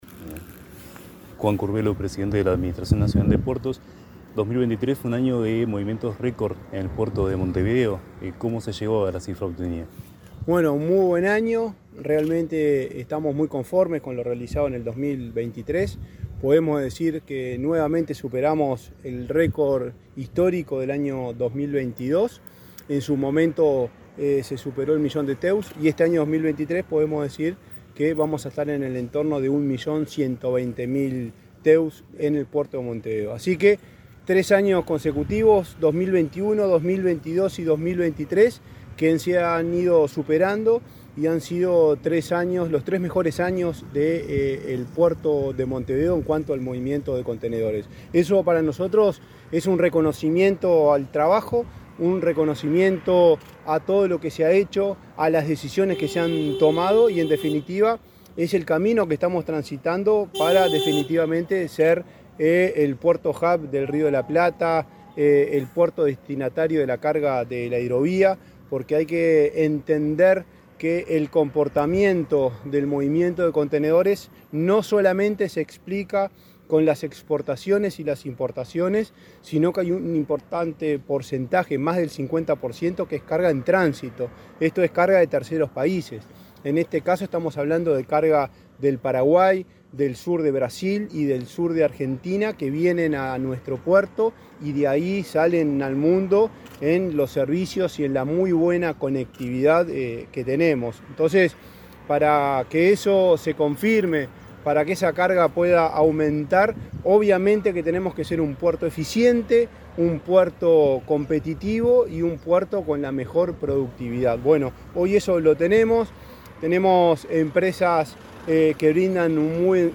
Entrevista al presidente de ANP, Juan Curbelo
Entrevista al presidente de ANP, Juan Curbelo 04/01/2024 Compartir Facebook X Copiar enlace WhatsApp LinkedIn El presidente de la Administración Nacional de Puertos (ANP), Juan Curbelo, dialogó con Comunicación Presidencial en Piriápolis, departamento de Maldonado, acerca del movimiento de contenedores en 2023.